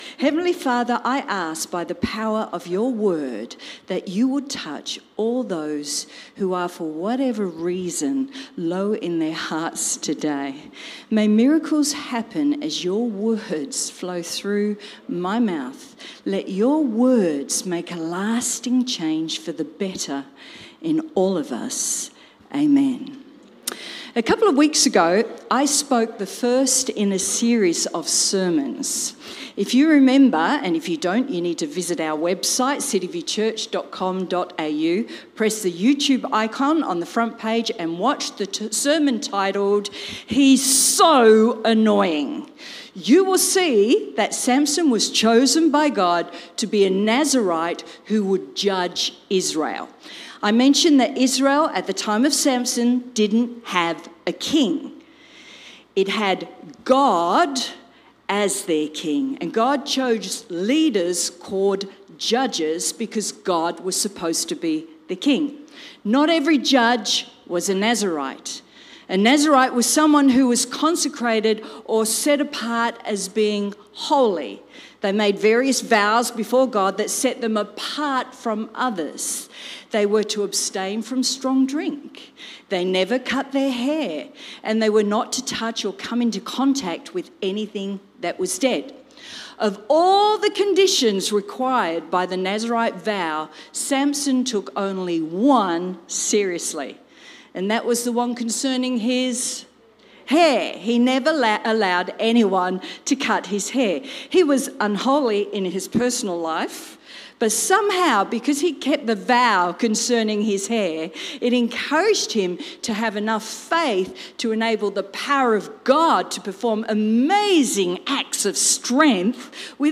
Does God hear us when we pray to him? Watch or listen to this sermon - part of the Nazerite 2 Series